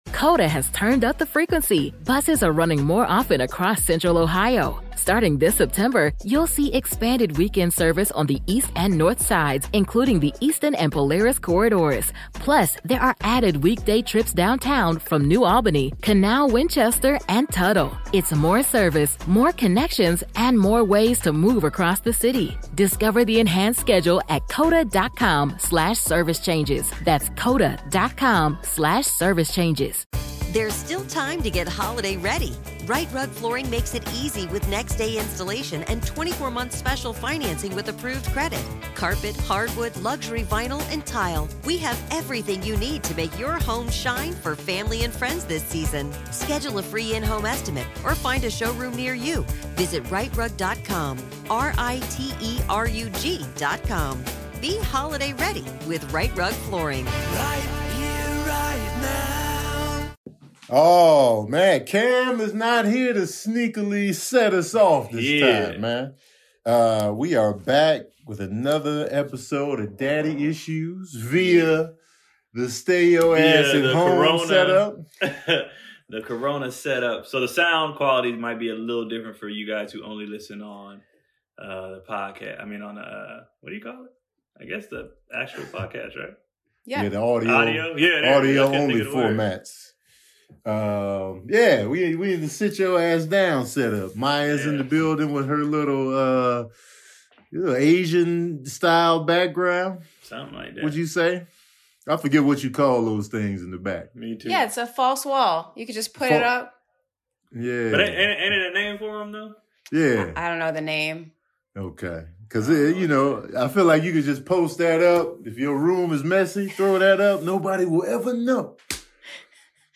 This week on the daddy issues podcast we're all reporting from home so please excuse the audio quality and the occasional lag. We're talking about the corona virus obviously but also keeping fit in a pandemic (use your kids as weights) and how's the shopping going in LA.